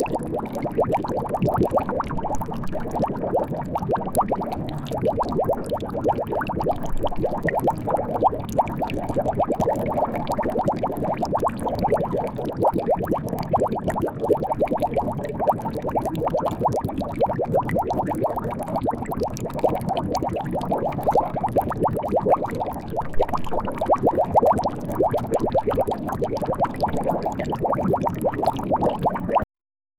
bubbling-lava-kqsggptm.wav